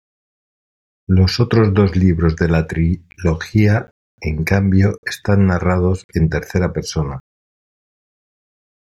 tri‧lo‧gí‧a